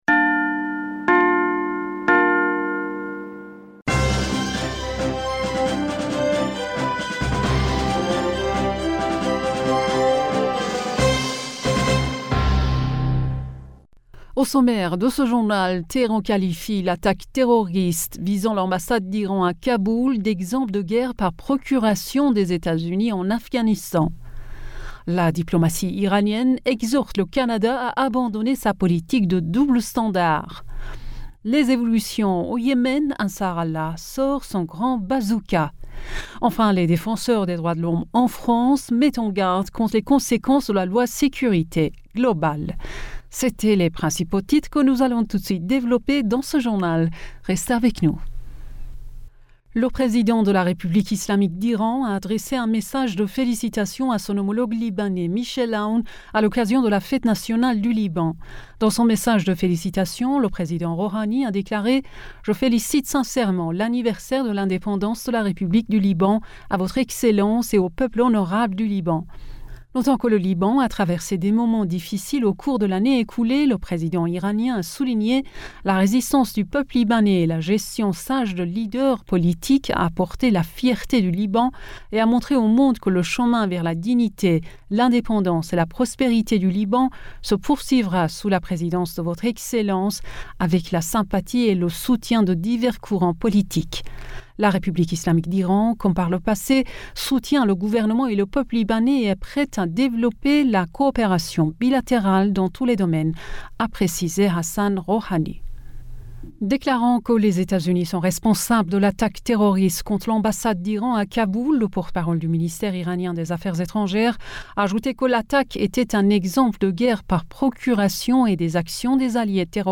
Bulletin d'informationd u 22 November 2020